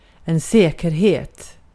Ääntäminen
IPA : /ə.ˈʃʊəɹ.əns/